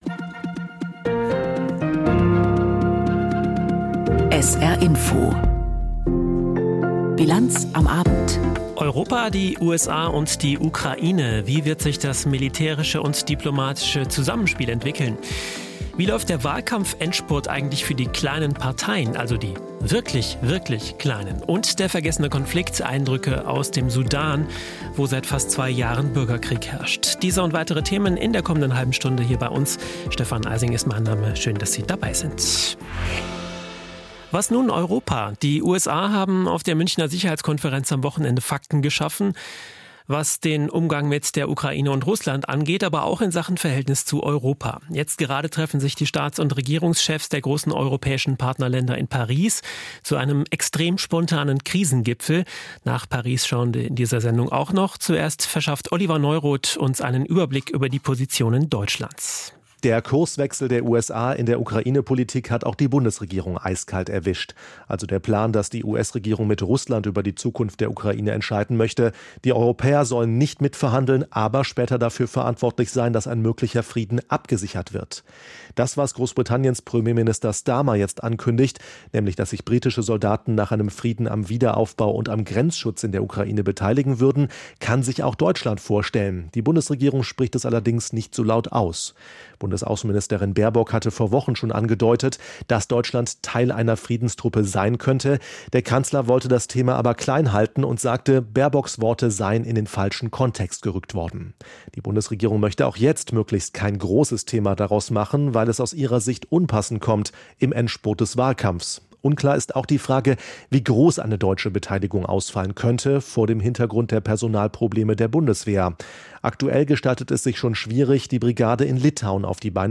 Aktuelles und Hintergründe zu Entwicklungen und Themen des Tages aus Politik, Wirtschaft, Kultur und Gesellschaft in Berichten und Kommentaren.